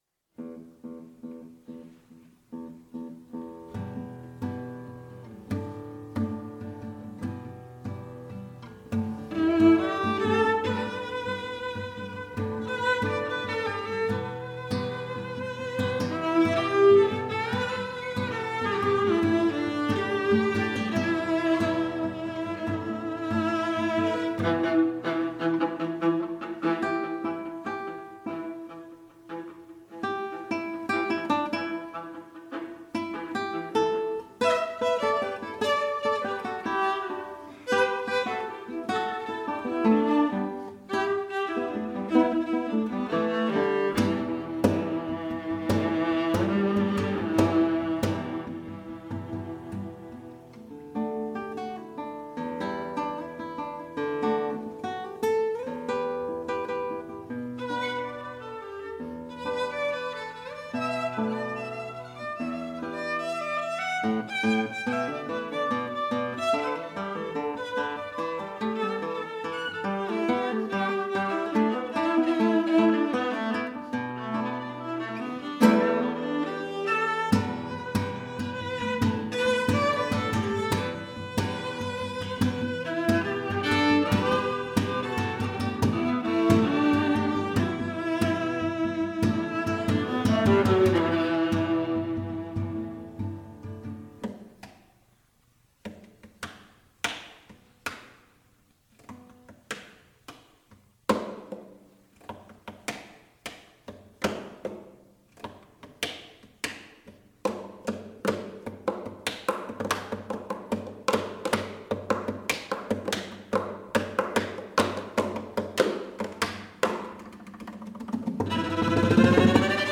for Viola and Guitar (2018)